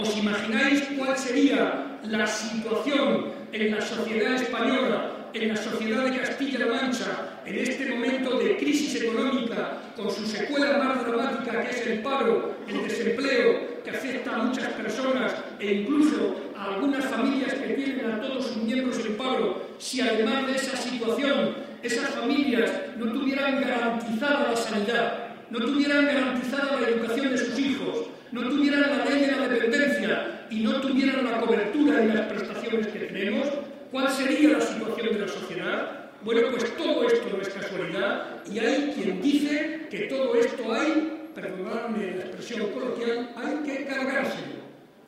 El secretario regional del PSOE y presidente de C-LM, participó en la tradicional comida de Navidad de los socialistas de Albacete.
Cortes de audio de la rueda de prensa